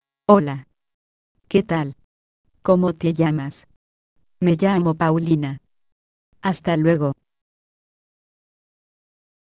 Texte de d�monstration lu par Paulina (Nuance RealSpeak; distribu� sur le site de Nextup Technology; femme; espagnol mexicain